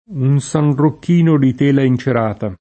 sanrocchino [Sanrokk&no] o sarrocchino [Sarrokk&no] s. m. («mantello») — es.: un sanrocchino di tela incerata [